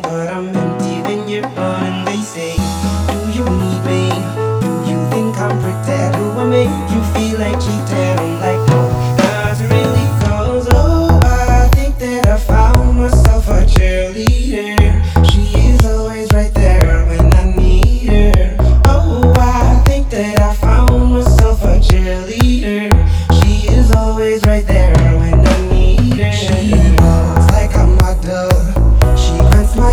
• Reggae fusion, deep house